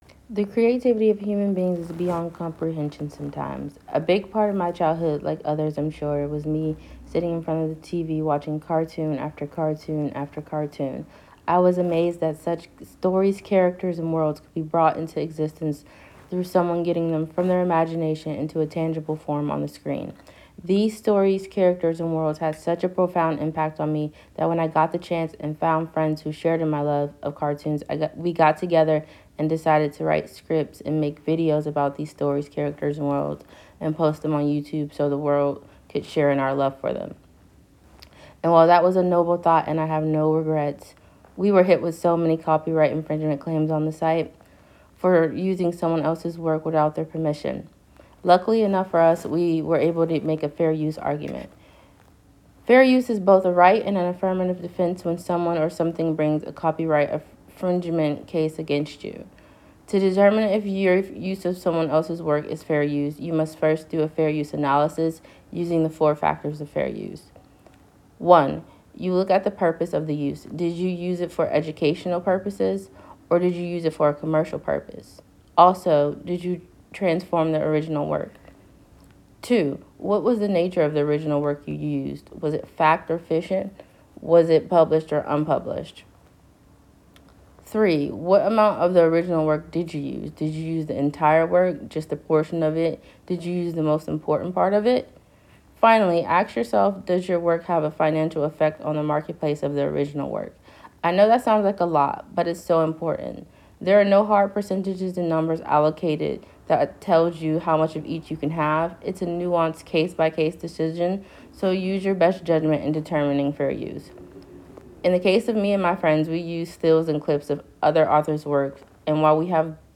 Student Voices